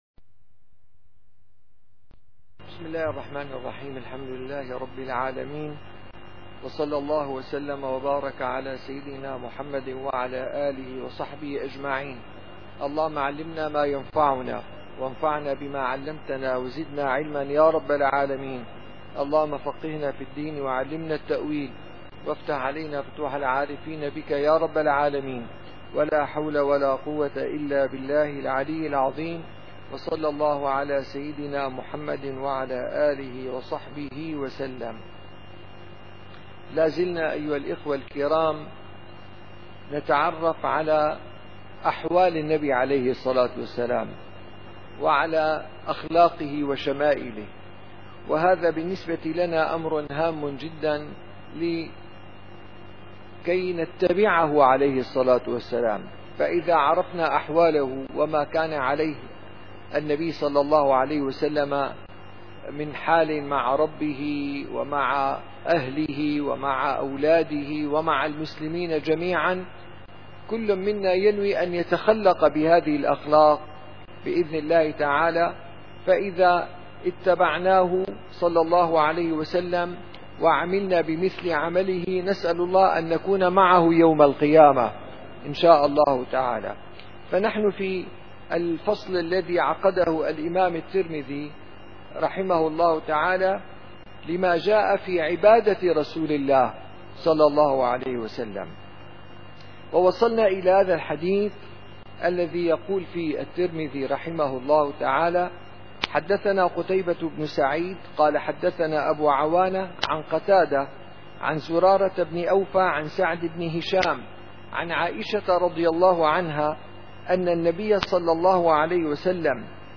- الدروس العلمية - الشمائل المحمدية - الشمائل المحمدية / الدرس السابع والعشرون :باب ما جاء في عبادة رسول الله صلى الله عليه وسلم (2)